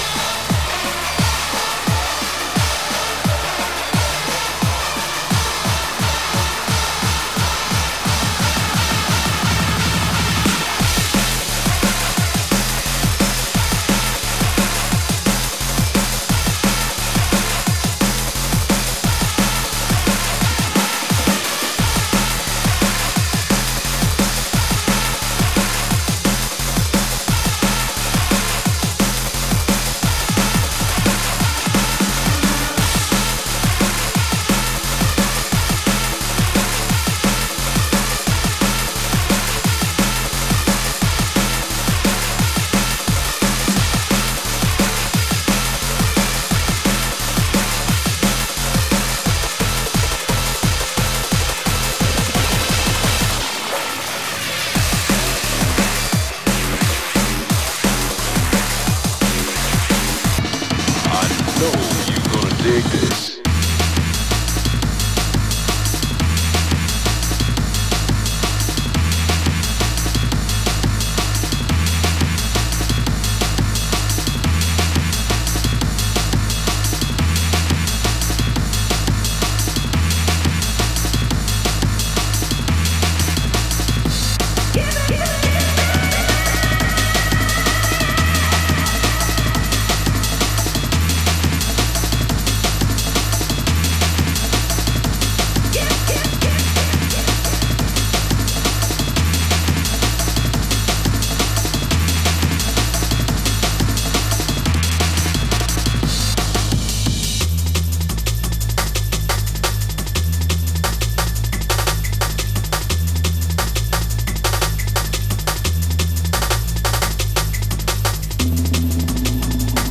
Light surface marks do not affect playback.